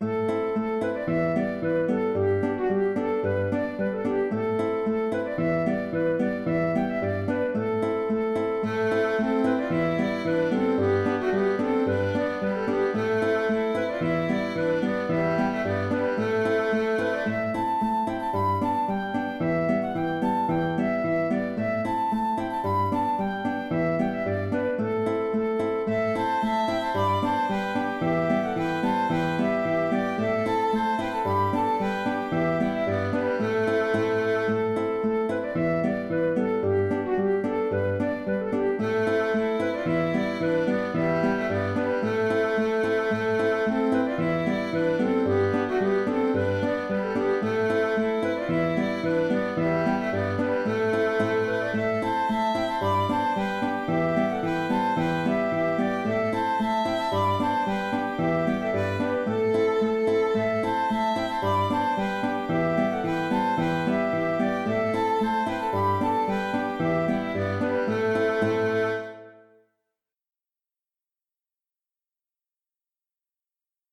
Cette polka (en Am, donc jouable sur un diato sol/do à 2 rangées) ) a fait partie du répertoire de mon groupe "Draft Company", en enchainement avec d'autres thèmes. Le contrechant est une version un peu modifiée d'une première version (au cas où vous auriez eu celle-ci). C'est une polka traditionnelle (peut-être sans nom?)